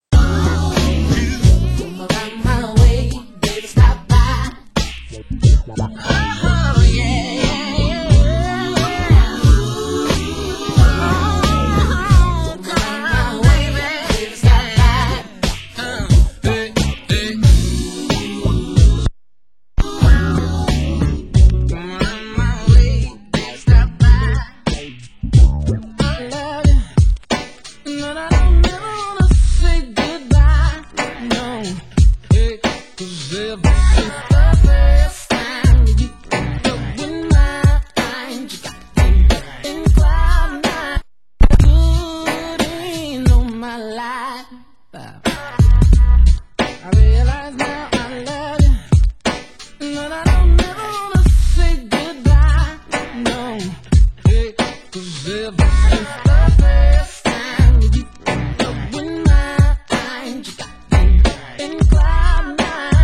full crew R&B mix